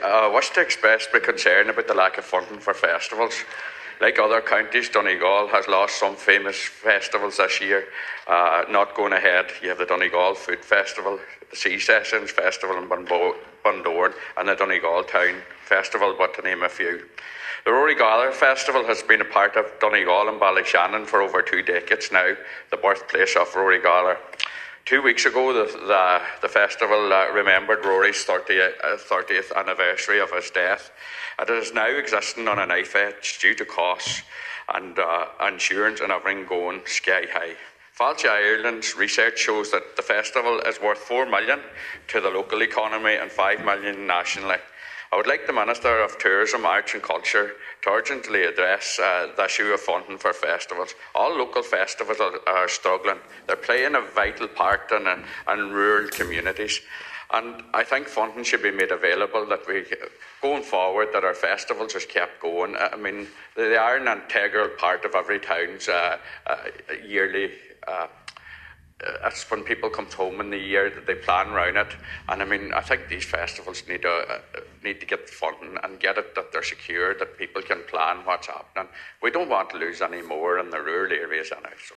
The Seanad has heard of the need for support of festivals in the Northwest.
Senator Boyle says the Ballyshannon festival is a major boost for the local economy: